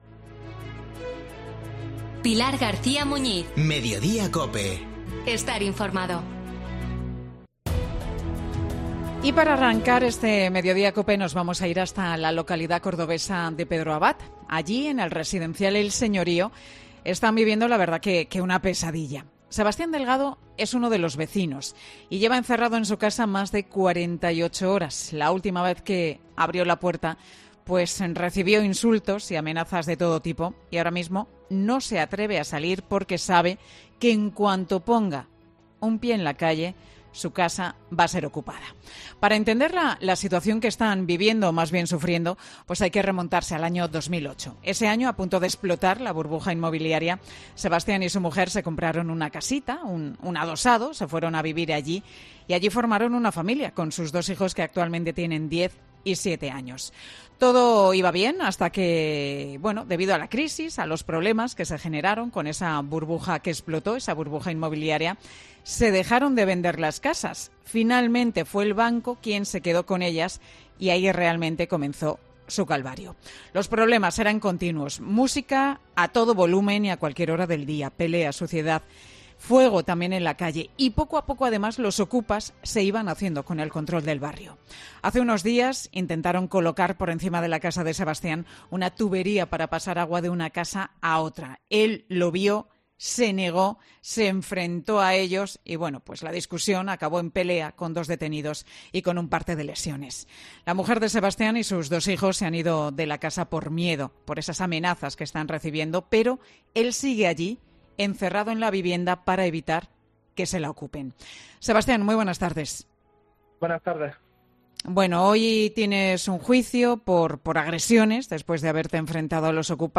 Hablamos con él.